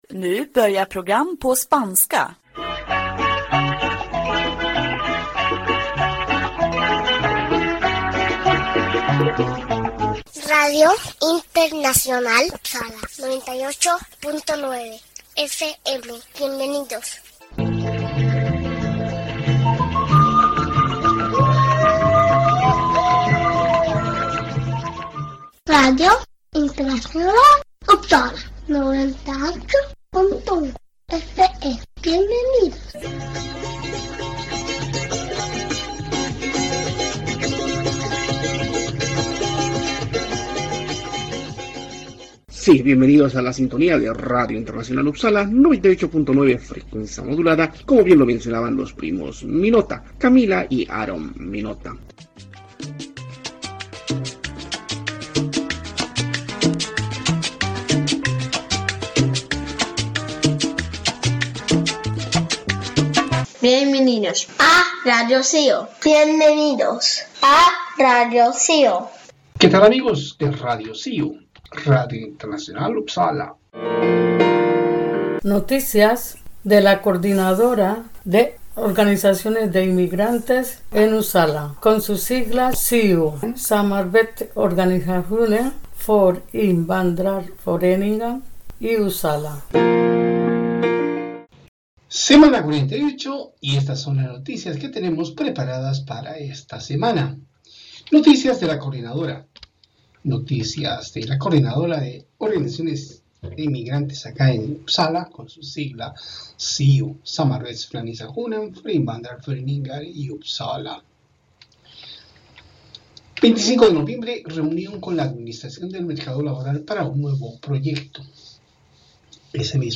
Un esfuerzo de la Coordinadora de organizaciones de inmigrantes en Uppsala – Suecia.SIU produce radio en más de 10 diferentes idiomas, entre ellos español. Sí, radio de cercanías en Uppsala se emite domingo a domingo a horas 18:30.